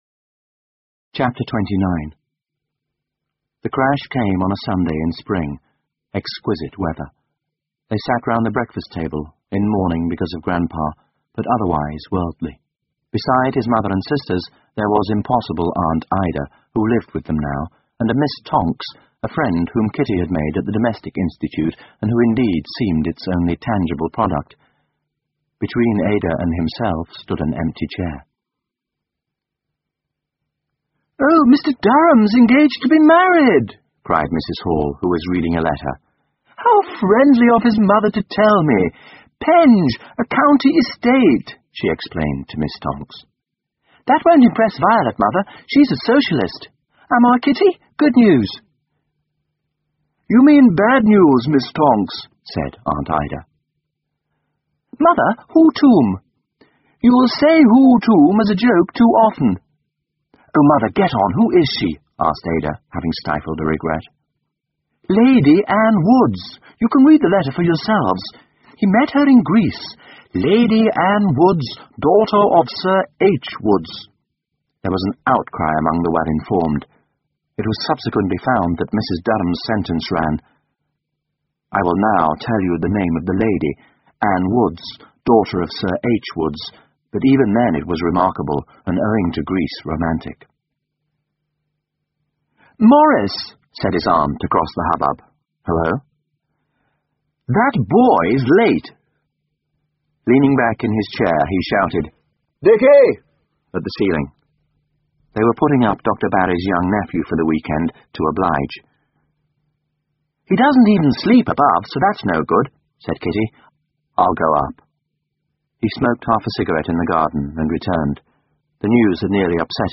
英文广播剧在线听 Maurice 莫瑞斯 - E. M. Forster 29 听力文件下载—在线英语听力室